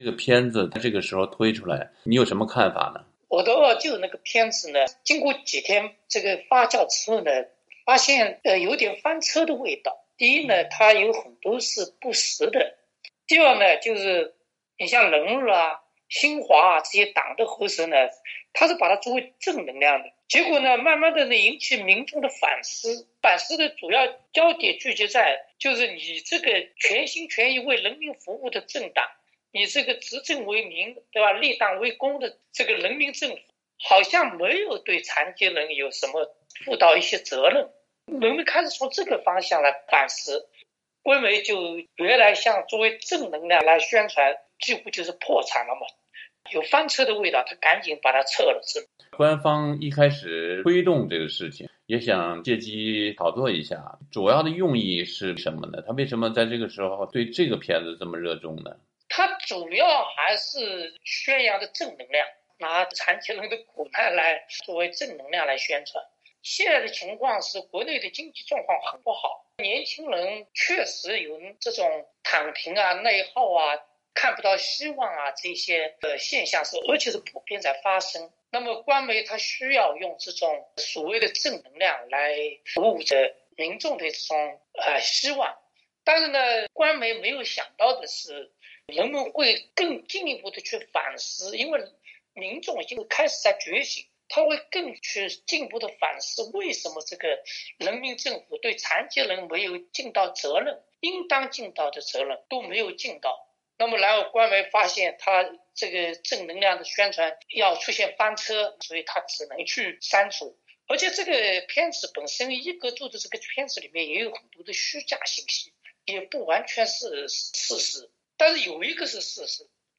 一位高位截瘫残障者点评《二舅》：中国刚吃饱饭不要瞎折腾